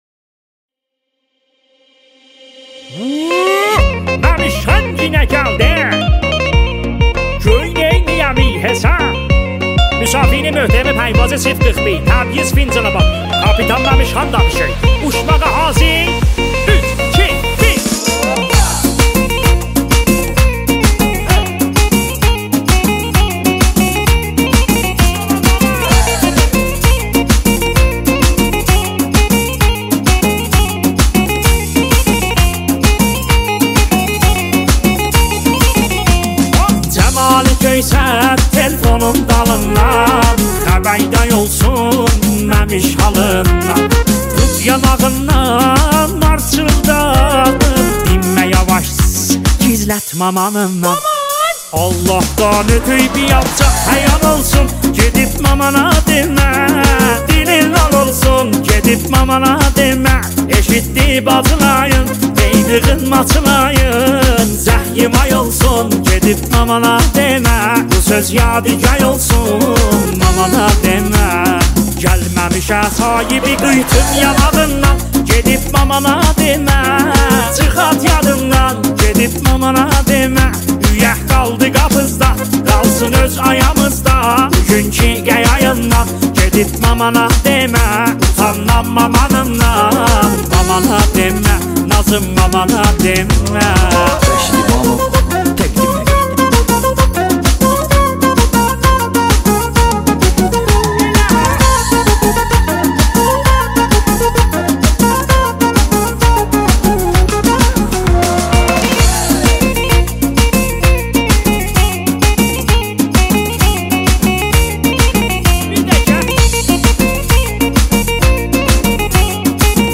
دانلود ریمیکس همین موزیک